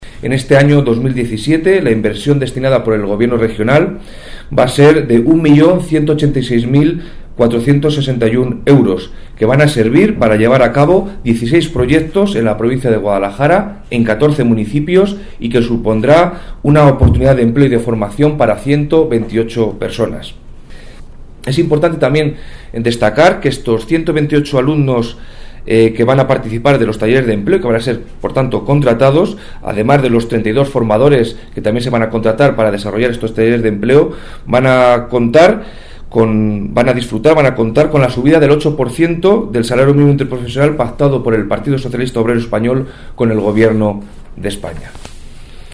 El delegado de la Junta en Guadalajara habla de la inversión del Gobierno regional en los talleres de empleo que se llevarán a cabo en la provincia de Guadalajara en 2017